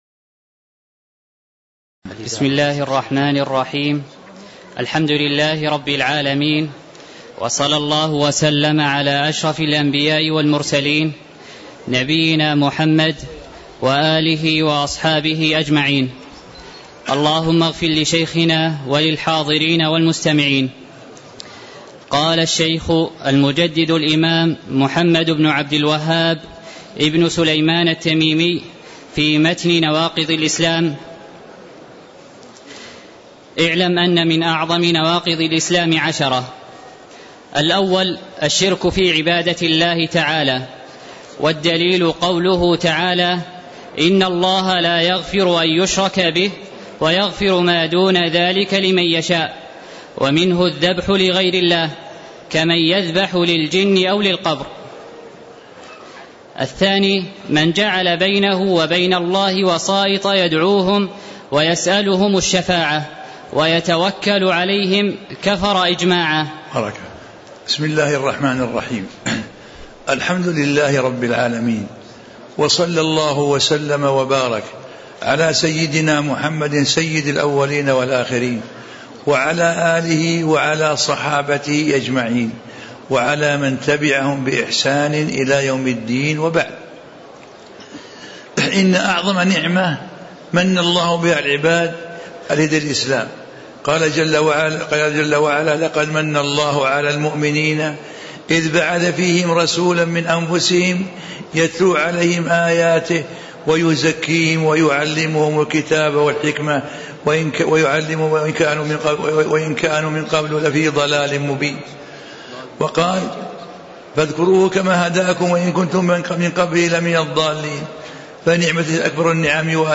تاريخ النشر ٧ صفر ١٤٣٧ المكان: المسجد النبوي الشيخ: سماحة المفتي الشيخ عبدالعزيز بن عبدالله آل الشيخ سماحة المفتي الشيخ عبدالعزيز بن عبدالله آل الشيخ 001الناقض الأولّ الشرك في عبادة الله تعالى